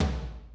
Index of /90_sSampleCDs/Zero-G Groove Construction (1993)/Drum kits/New Jack Swing/Kick